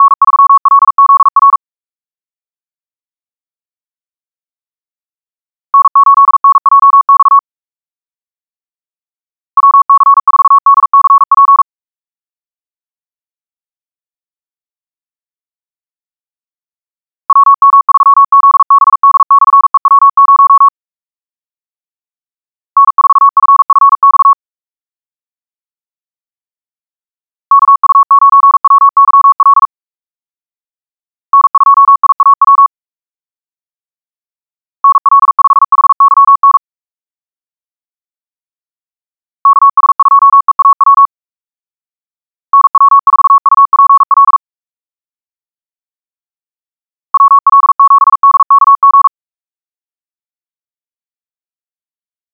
1. The generated Morse codes are almost free of key-clicks in sound.
A successive bursts of word-codes can be put out at random intervals.
<-- 158cpm (=47wpm(paris))　 1111Hz sinusoidal waveform
SampleHST1.wav